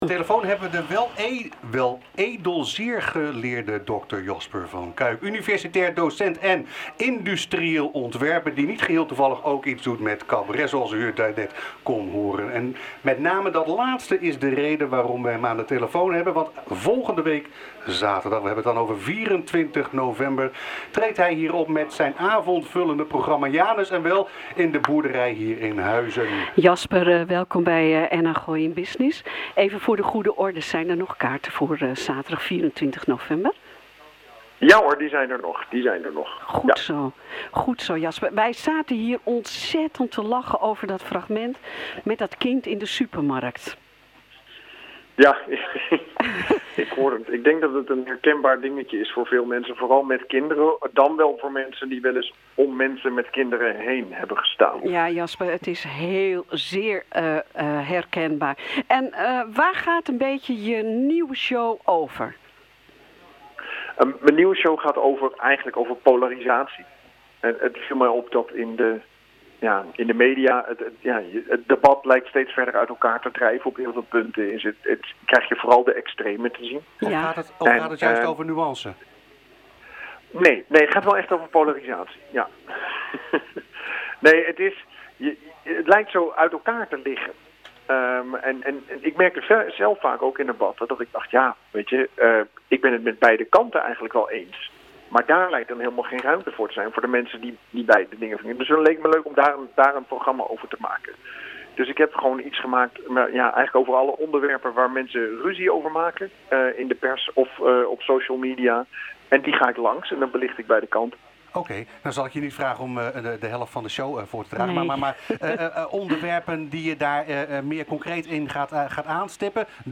Met name dat laatste is de reden waarom we hem aan de telefoon hebben.